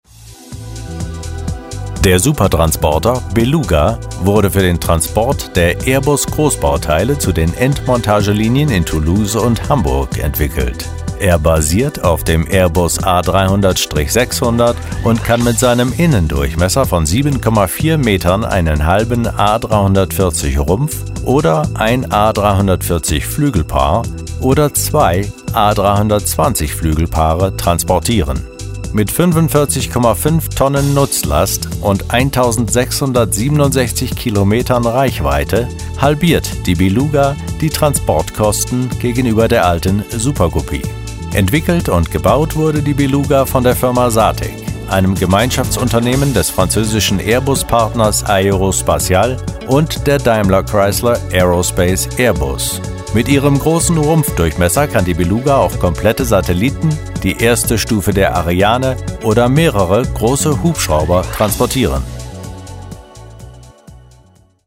Stimme: Weich, warm, werblich - authoritär. Die sonore Stimme eines Erzählers
Kein Dialekt
Sprechprobe: Industrie (Muttersprache):